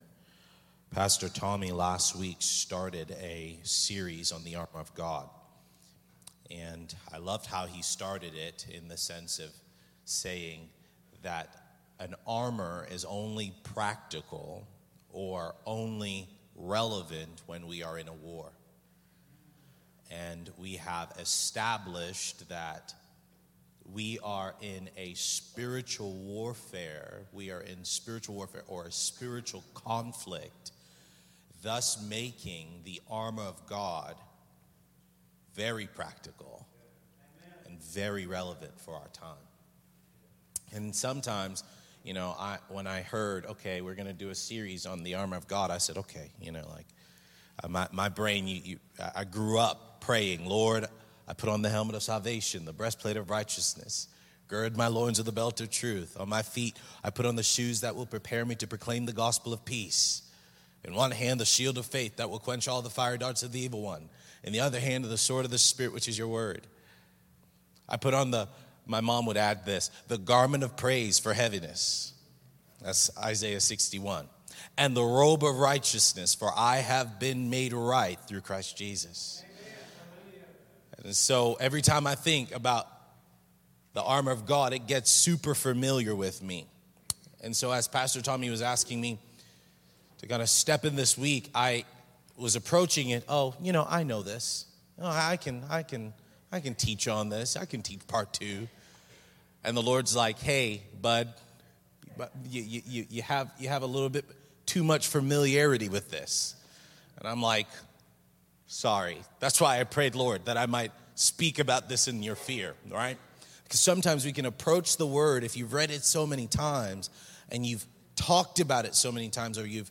Sermons | SpiritLife Church